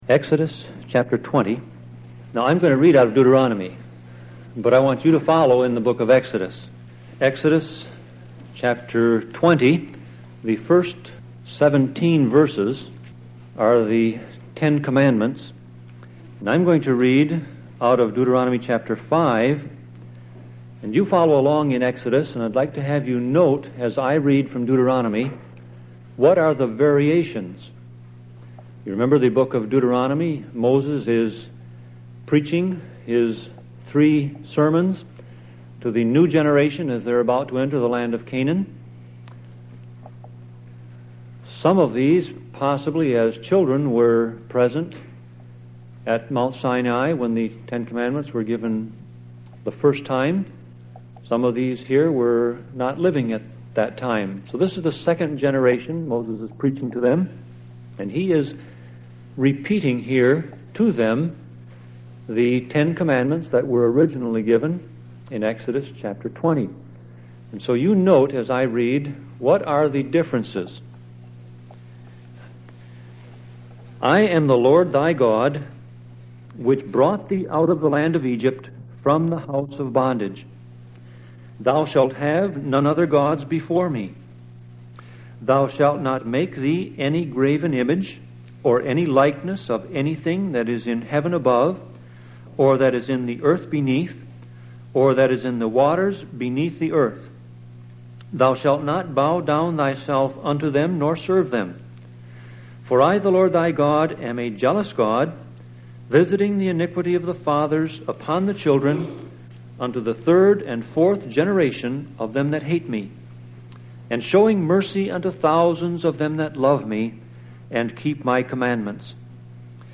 Sermon Audio Passage: Deuteronomy 5:7-21 Service Type